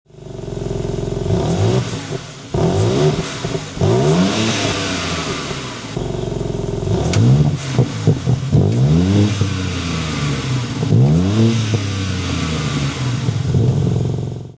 IOM-green-revs-.mp3